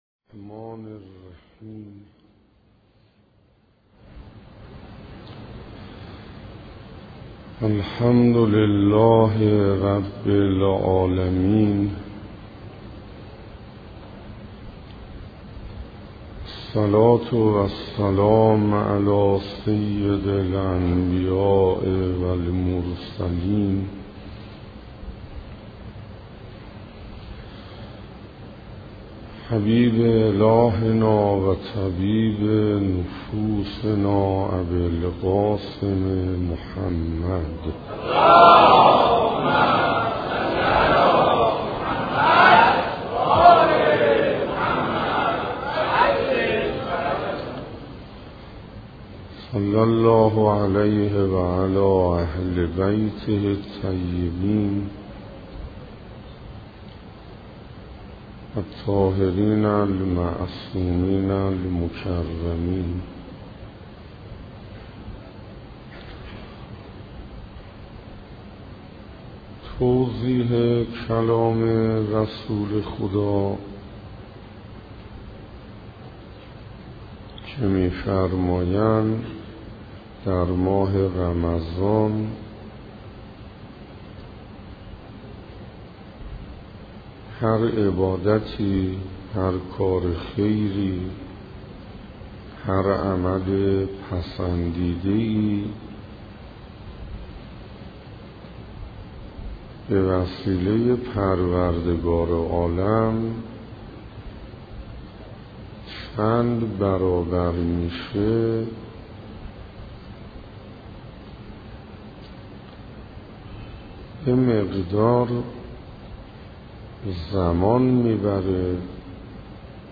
سخنرانی حجت الاسلام حسین انصاریان